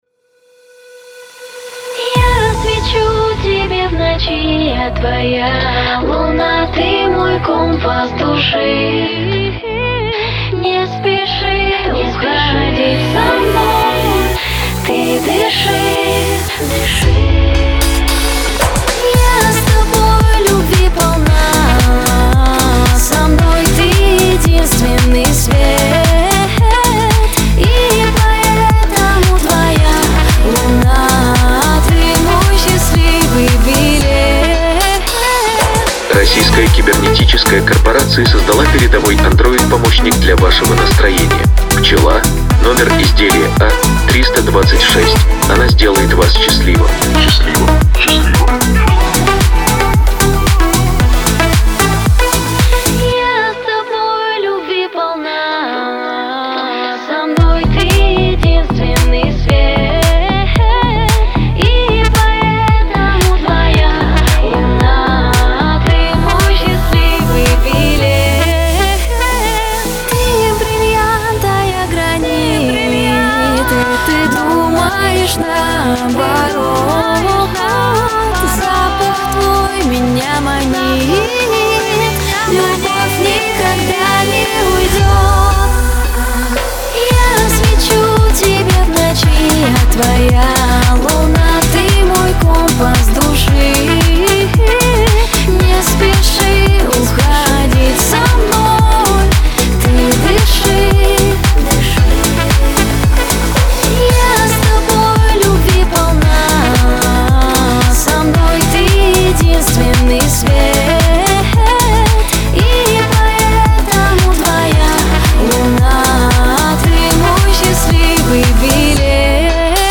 эстрада
диско